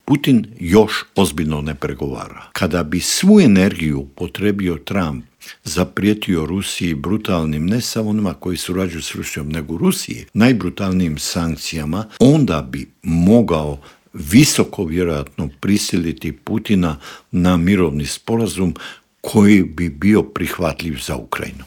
ZAGREB - Uoči 34. godišnjice međunarodnog priznanja Hrvatske i 28. obljetnice završetka mirne reintegracije hrvatskog Podunavlja u Intervju Media servisa ugostili smo bivšeg ministra vanjskih poslova Matu Granića, koji nam je opisao kako su izgledali pregovori i što je sve prethodilo tom 15. siječnju 1992. godine.